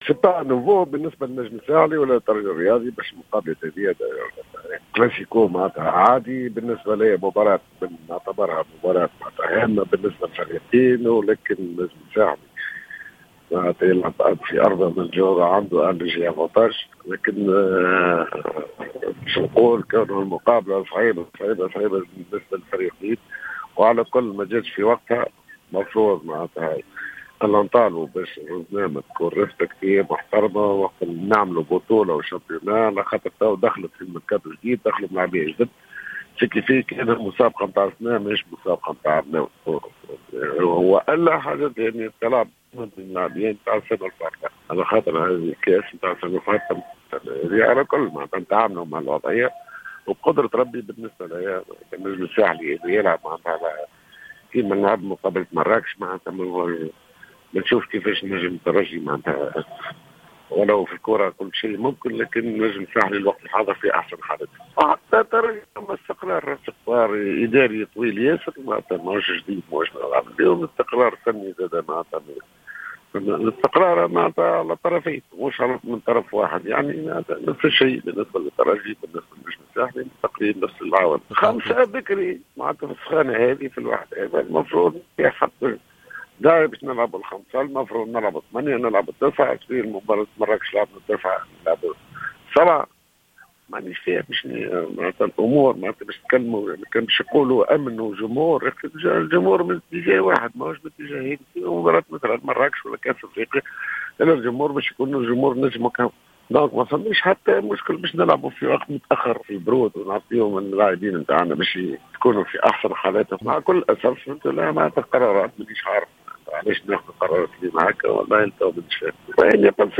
أكد مدرب النجم الرياضي الساحلي في تصريح لراديو جوهرة أف أم أن فريقه على أتم الإستعداد لمواجهة الترجي مساء يوم غد الثلاثاء لحساب الدور ربع النهائي من مسابقة الكأس رغم أن توقيت المقابلة غير مناسبة بإعتبار الإلتزامات القارية للنجم و إلى اللخبطة الحاصلة في الرزنامة العامة للمسابقات .